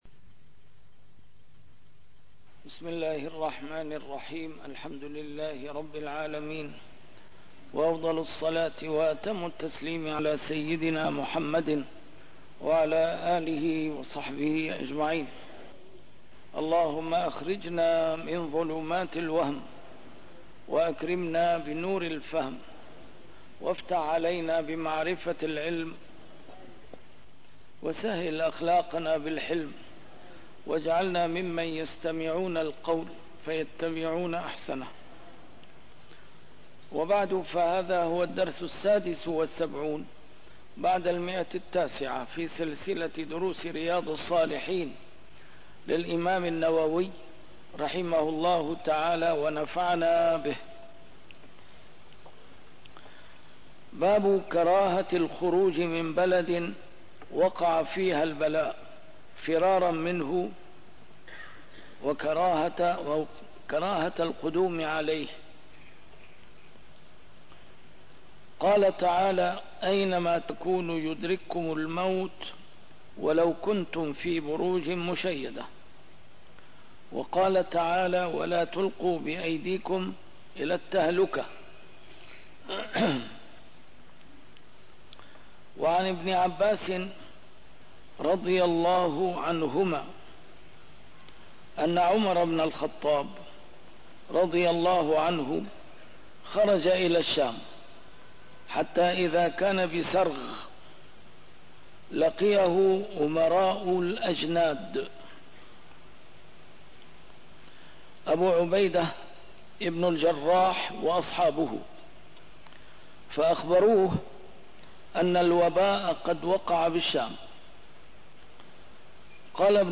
A MARTYR SCHOLAR: IMAM MUHAMMAD SAEED RAMADAN AL-BOUTI - الدروس العلمية - شرح كتاب رياض الصالحين - 976- شرح رياض الصالحين: كراهة الخروج من بلد وقع فيها البلاء وكراهة القدوم عليه